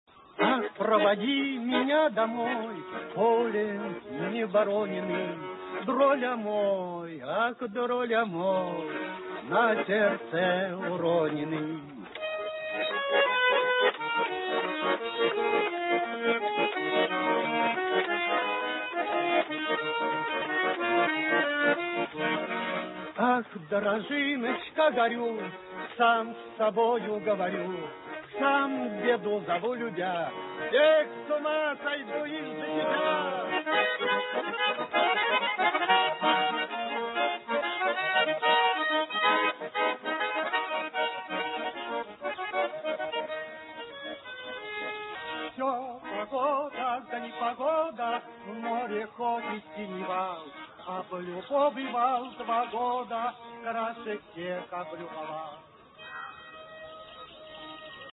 А за кадром женские голоса, русская песня.